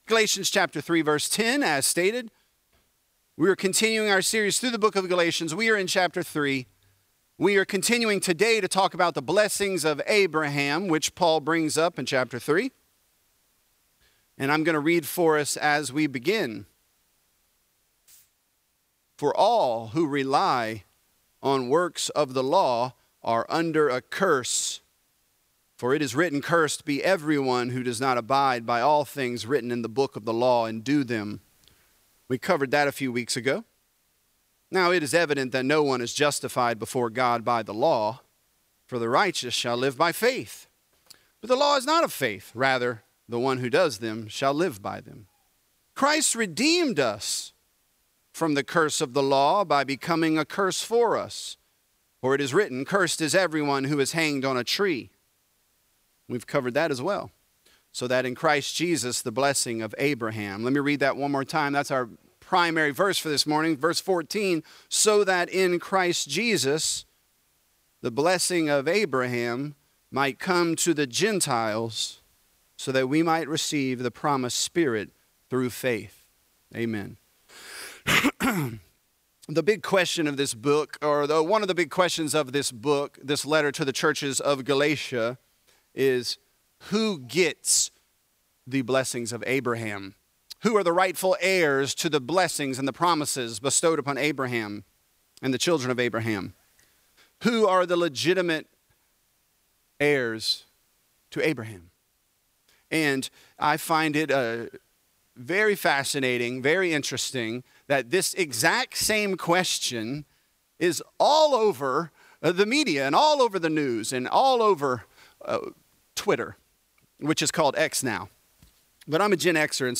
This is a part of our sermon series on the book of Galatians.